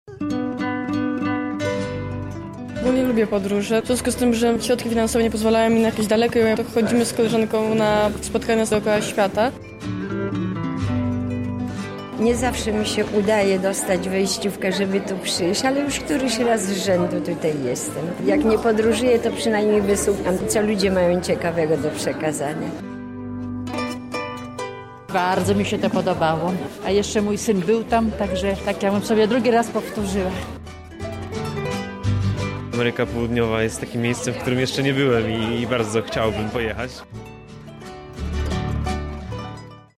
O swoich wrażeniach z wczorajszego spotkania opowiadają uczestnicy.